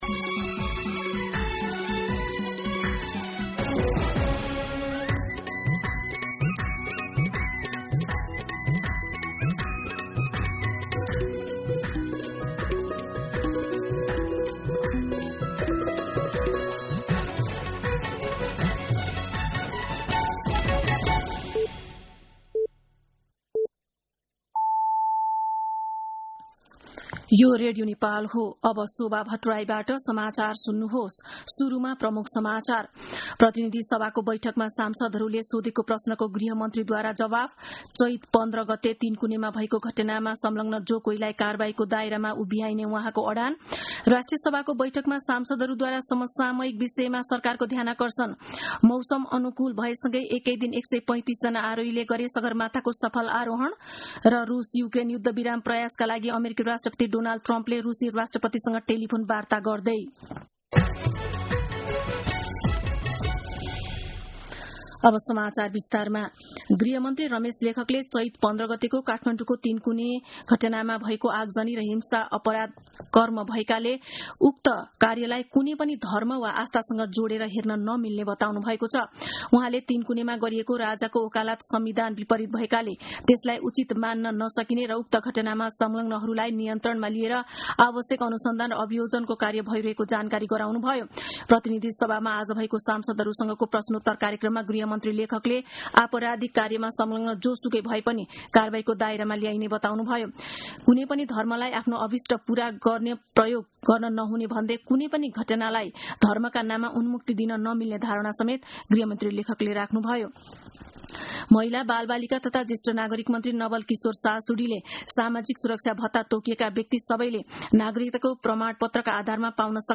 दिउँसो ३ बजेको नेपाली समाचार : ५ जेठ , २०८२